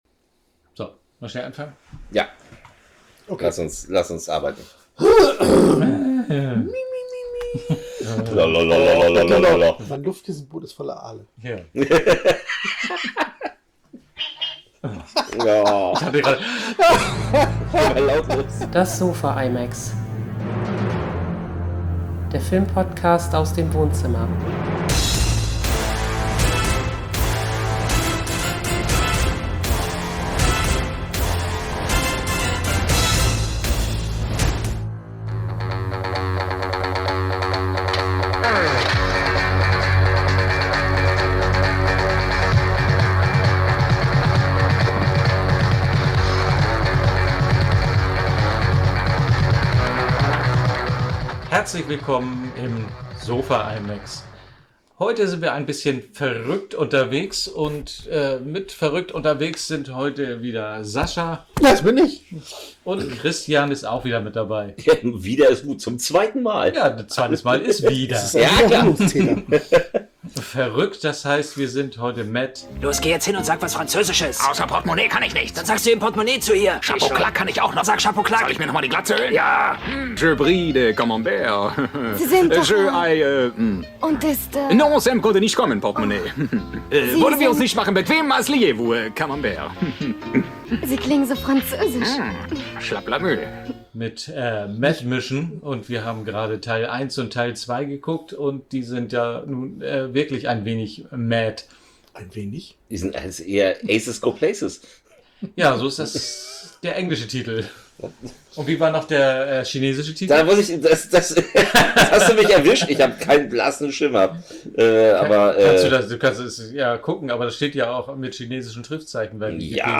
Freunde besprechen Filme im Wohnzimmer.